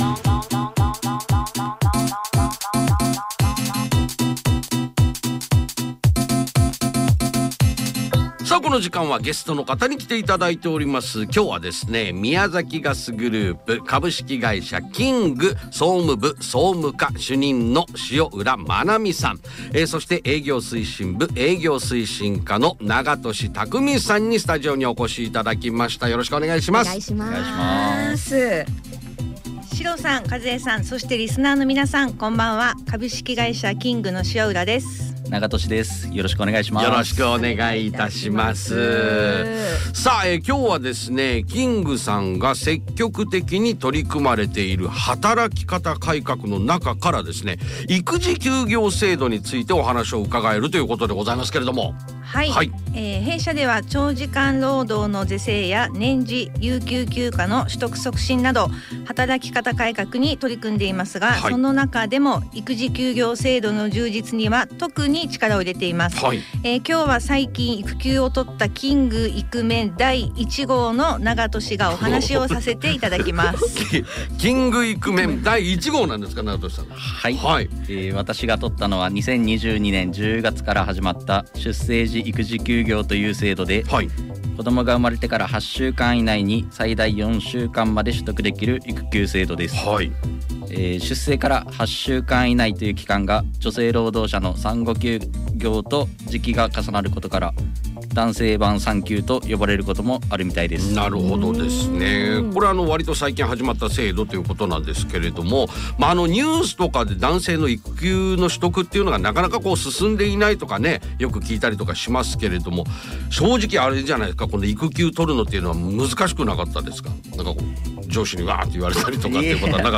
エフエム宮崎で企業紹介が放送されました。